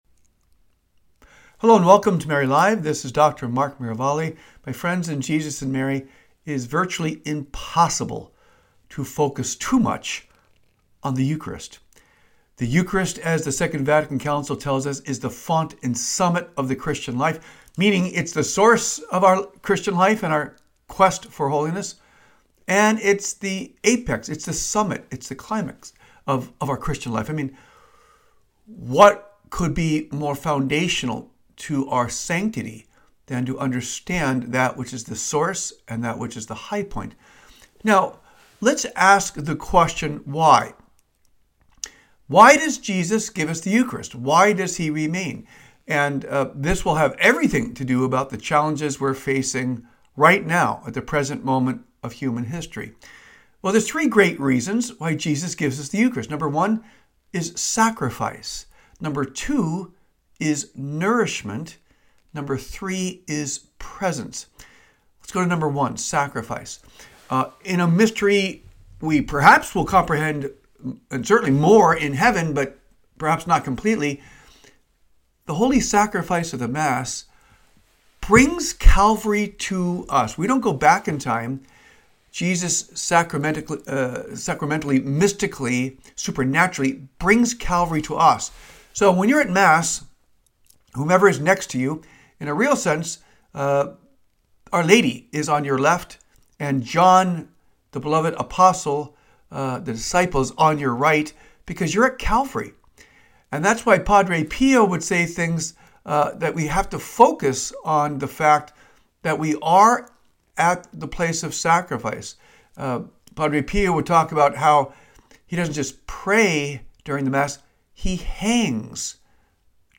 a dynamic discussion on the Eucharist and Our Lady in this week's Mary Live.